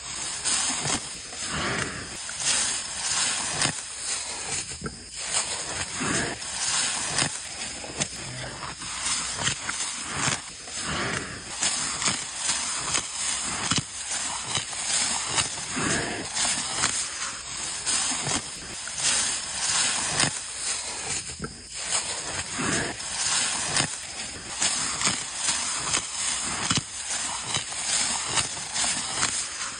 Tiếng Trâu, bò… gặm ăn cỏ, ăn thức ăn…
Thể loại: Tiếng vật nuôi
Description: Tiếng Bò, tiếng động vật ăn cỏ, tiếng nông nghiệp, âm thanh của đồng ruộng cùng với tiếng trâu bò, tất cả hòa quyện tạo nên bản âm thanh độc đáo của vùng quê.
tieng-trau-bo-gam-an-co-an-thuc-an-www_tiengdong_com.mp3